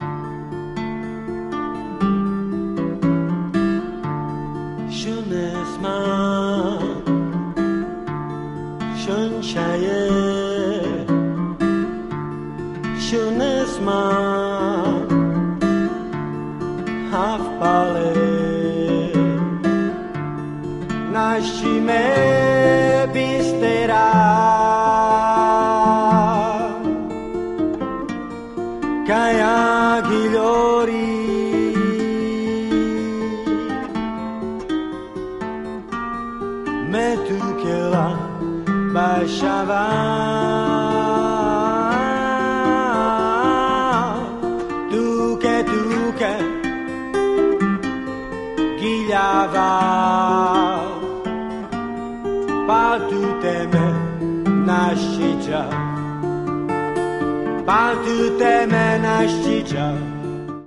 kontrabas, doprovodný zpěv, perkuse
kytary, zpěv, perkuse
mandolína, doprovodný zpěv